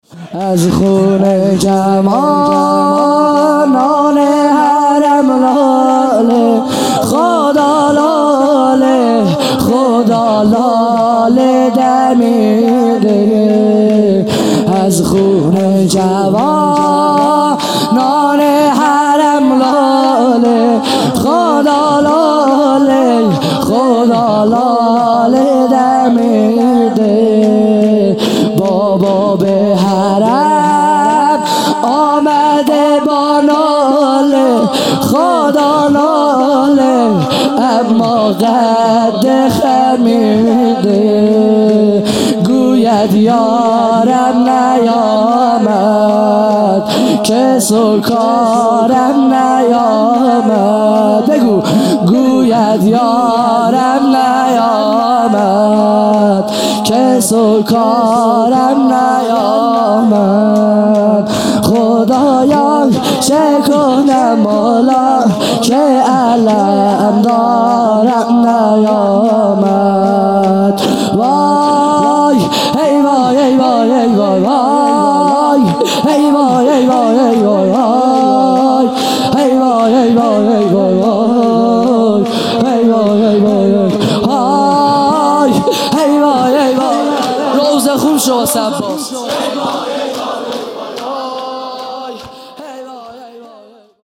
خیمه گاه - هیئت بچه های فاطمه (س) - شور | از خون جوانان حرم
محرم 1441 | صبح نهم